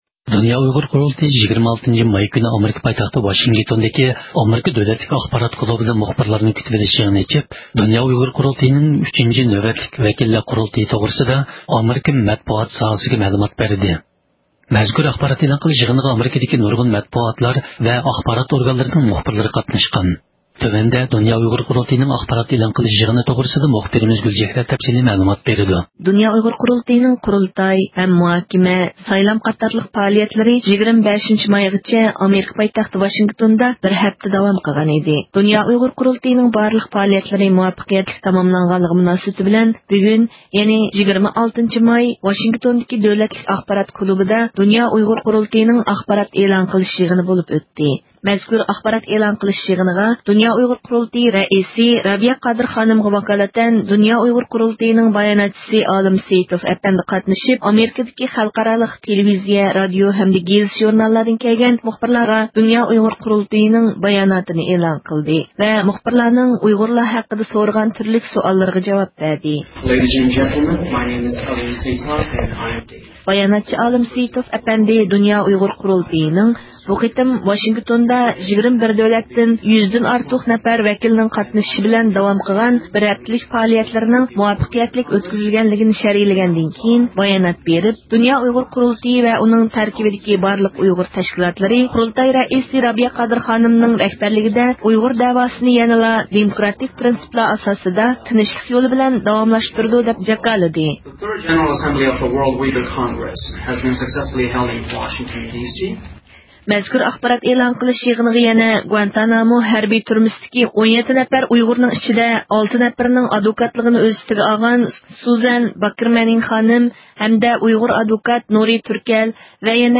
دۇنيا ئۇيغۇر قۇرۇلتىيى 26 – ماي كۈنى، ئامېرىكا پايتەختى ۋاشىنگىتونغا جايلاشقان ئامېرىكا دۆلەتلىك ئاخبارات كۇلۇبىدا، مۇخبىرلارنى كۈتىۋېلىش يىغىنى ئېچىپ، دۇنيا ئۇيغۇر قۇرۇلتىيىنىڭ 3 – نۆۋەتلىك ۋەكىللەر قۇرۇلتىيىنىڭ مۇۋەپپەقىيەتلىك تاماملانغانلىقى مۇناسىۋىتى بىلەن ئاخبارات ئېلان قىلدى.